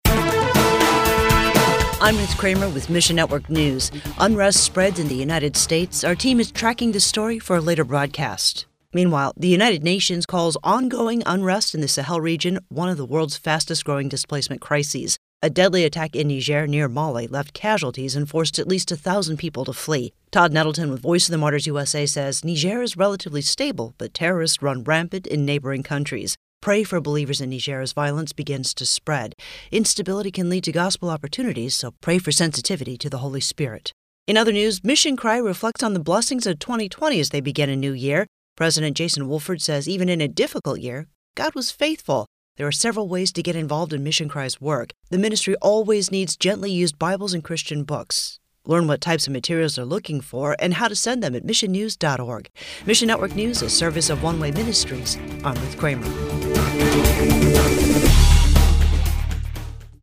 Audio Broadcast